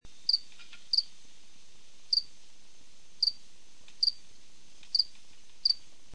cricket.mp3